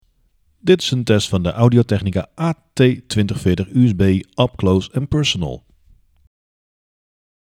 Voor de test hebben we de AT2040USB aangesloten op een MacBook Pro met Garageband om te zien hoe de microfoon het doet als we wat opnames gaan maken en vooral om te ervaren hoe de AT2040USB het in verschillende omstandigheden doet.
De helderheid bij beide scenario’s is goed, de stem is helder en natuurgetrouw, al horen we wel duidelijk dat er meer warmte in de opname zit zodra je met je mond dichter bij de microfoon komt.
AT2040USB-upclose.mp3